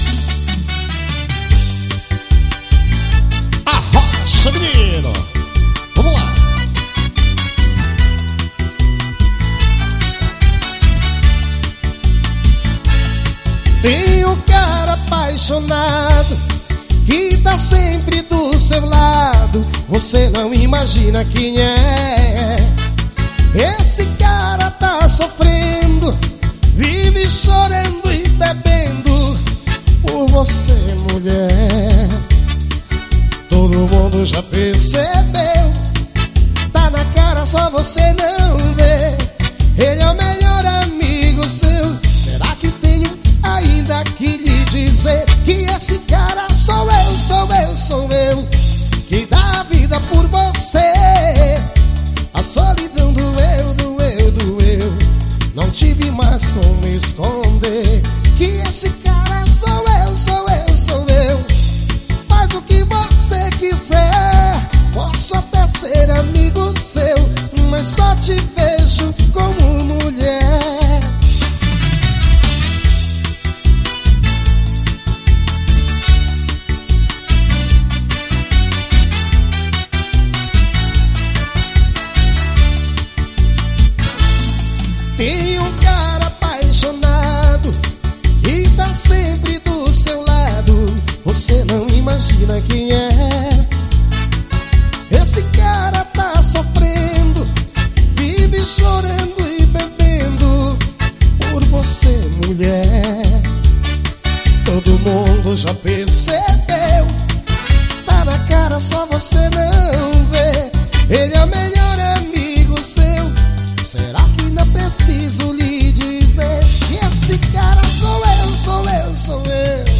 AO VIVO